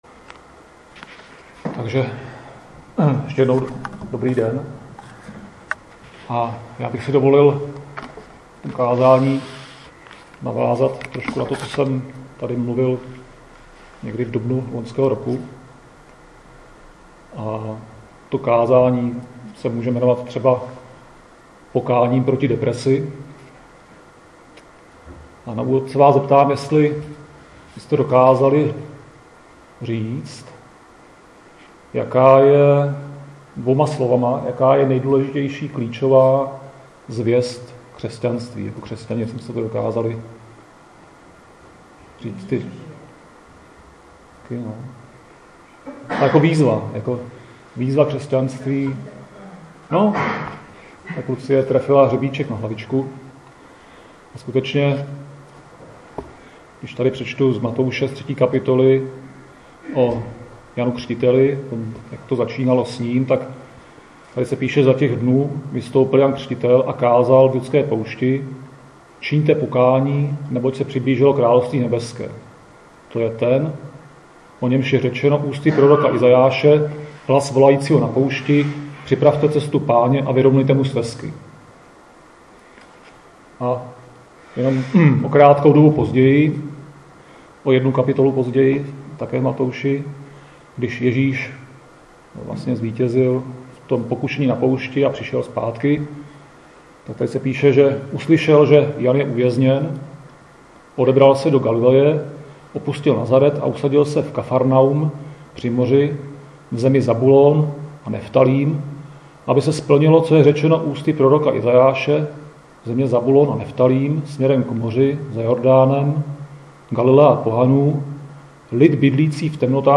Křesťanské společenství Jičín - Kázání 12.2.2017